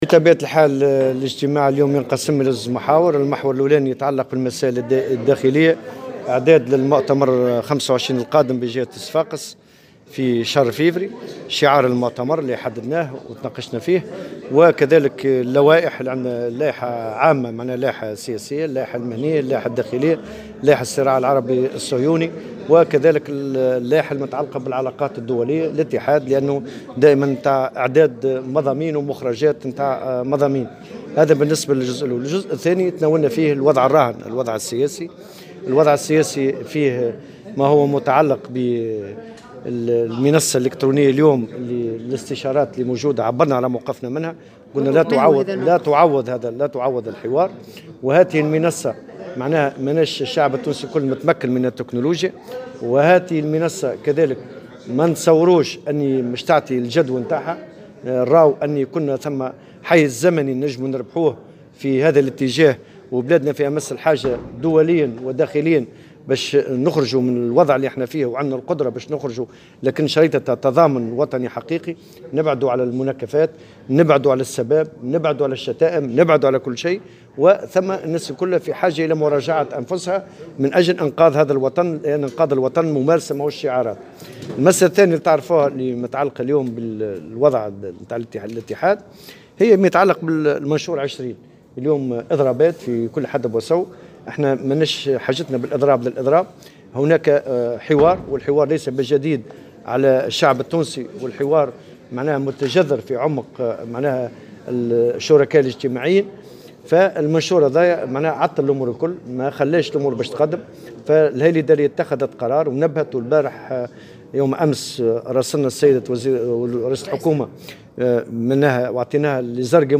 قال الأمين العام للاتحاد العام التونسي للشغل، نور الدين الطبوبي، في تصريح للجوهرة اف ام، إنه لا يمكن للاستشارة الالكترونية المزمع إجراؤها، أن تعوض الحوار، ولا يمكن للمنصة المعدة للغرض أن تكون في متناول جل التونسيين، وسيكون إجراء هذه الاستشارة دون جدوى، بحسب تعبيره.